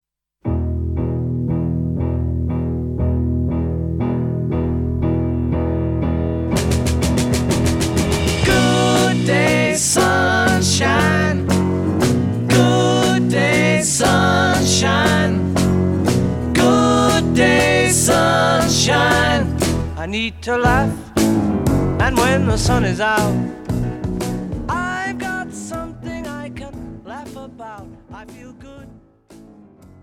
zpěv, basová kytara, klavír
bicí